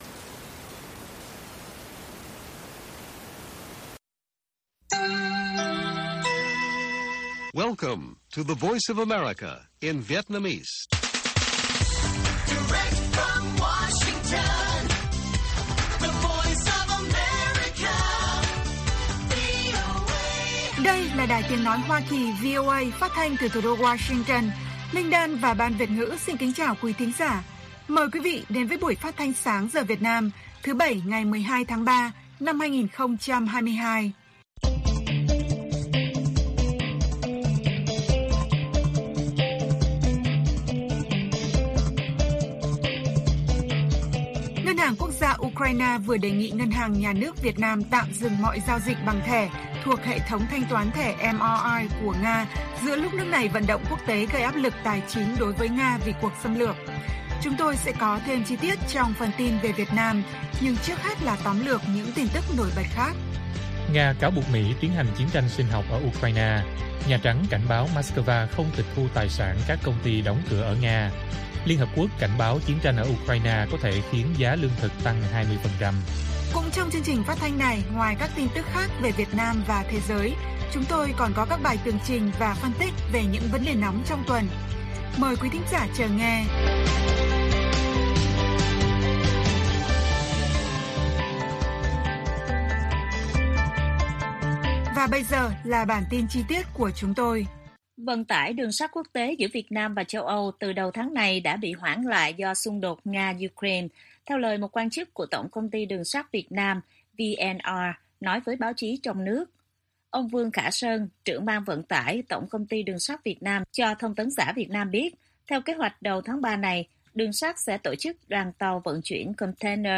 Nga cáo buộc Mỹ 'chiến tranh sinh học' ở Ukraine - Bản tin VOA